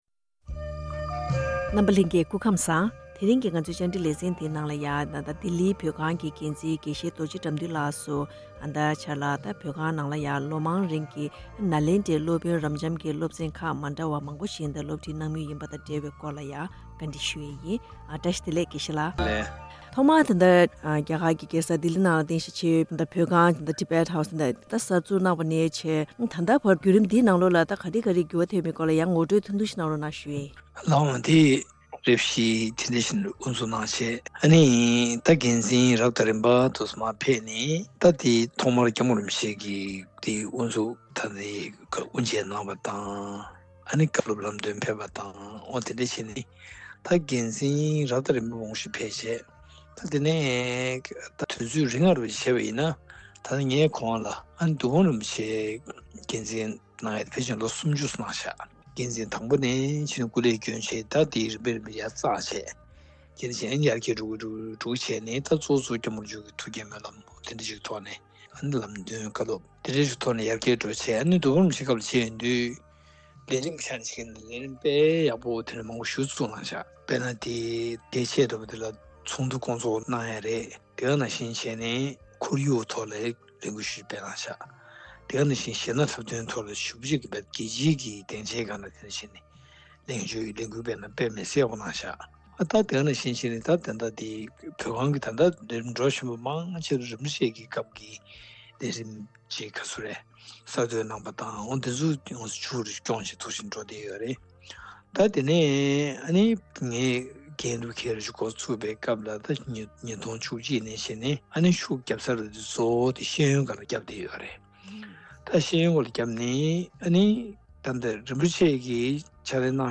བཀའ་འདྲི་ཞུས་ནས་ཕྱོགས་སྒྲིག་ཞུས་པ་ཞིག་གསན་གནང་གི་རེད།